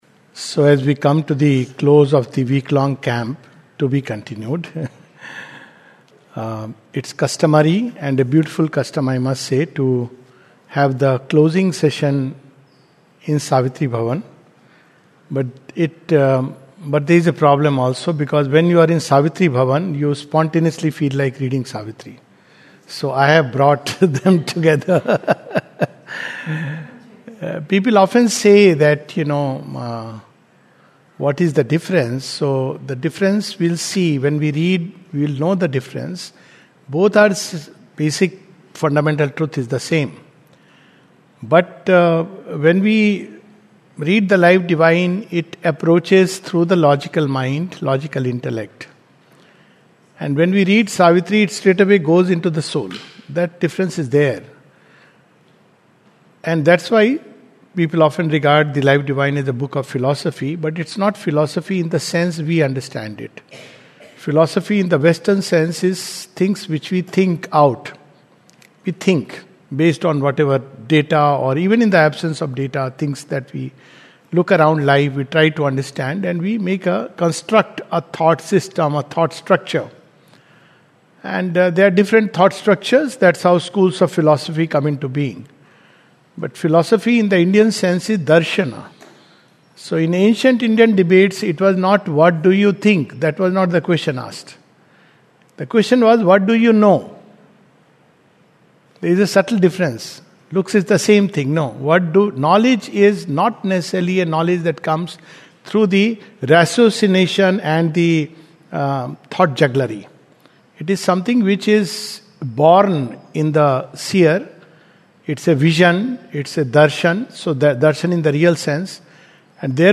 The Life Divine by Sri Aurobindo study camp No 6. 28th February 2026 at Savitri Bhavan, Auroville - 605101, India.